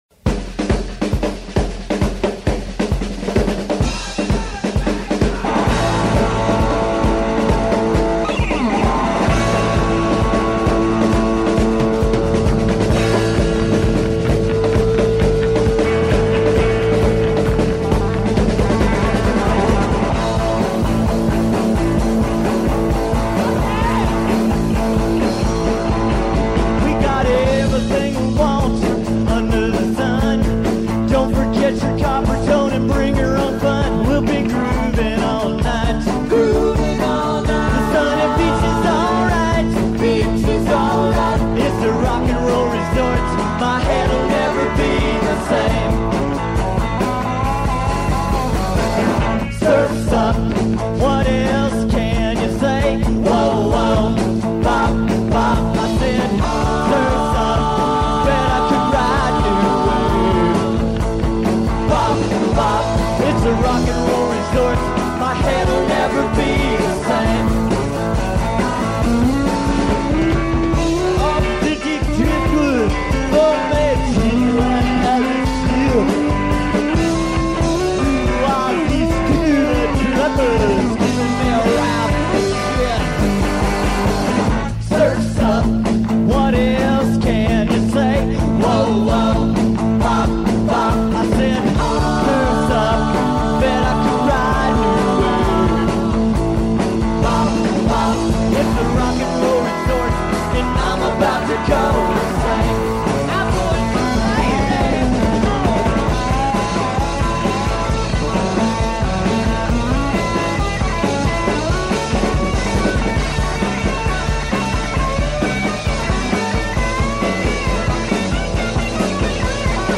lead guitar/vocals
drums/vocals
bass/vocals
rhythm guitar/vocals